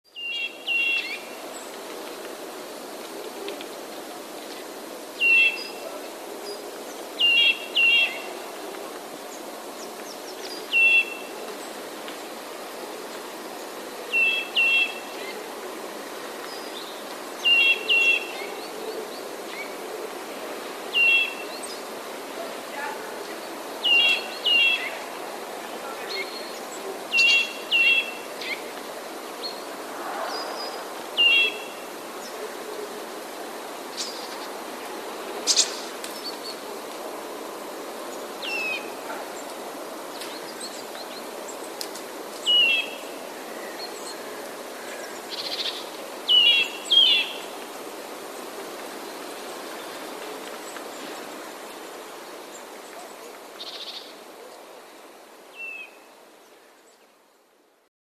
Kowalik - Sitta europaeus
Kowalik śpiewa czasami trochę
schrypniętym głosem.
kowalik_chrypka.mp3